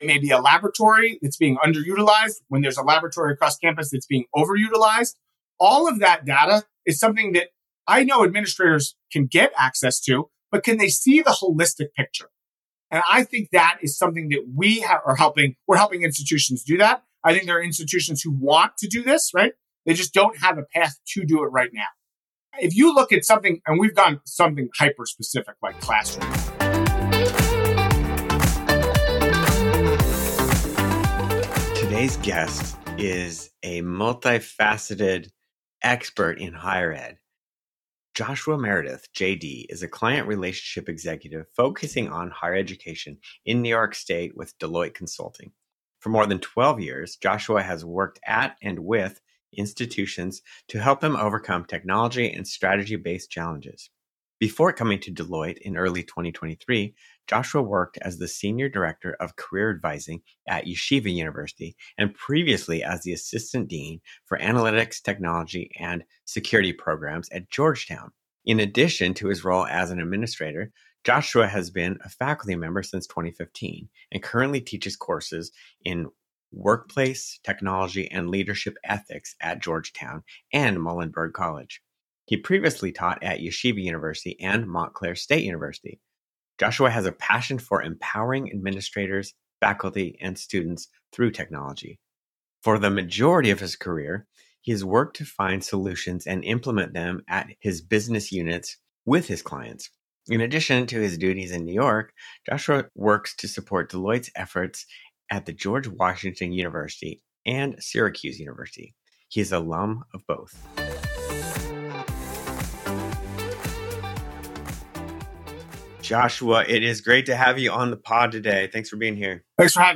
Each episode features interviews with leading experts, educators, technologists and solution providers, who share their insights on how technology can be used to improve student engagement, enhance learning outcomes, and transform the educational experience.